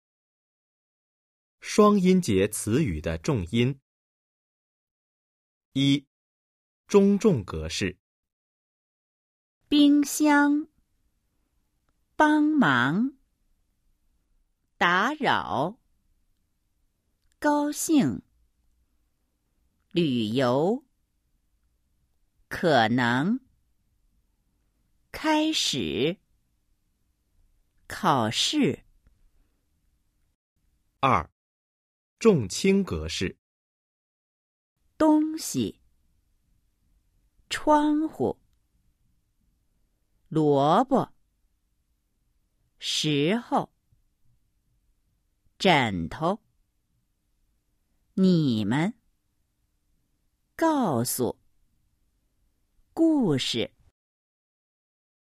■ 双音节词语的重音 Trọng âm của từ có hai âm tiết  💿 01-05
Đa số từ có hai âm tiết thuộc cấu trúc “nhấn vừa + nhấn mạnh”, trong đó âm tiết thứ hai được đọc nhấn mạnh và kéo dài hơn.
Một số ít từ có hai âm tiết thuộc cấu trúc “nhấn mạnh + đọc nhẹ”, trong đó âm tiết thứ nhất được đọc nhấn mạnh và kéo dài hơn còn âm tiết thứ hai được phát âm nhẹ và nhanh.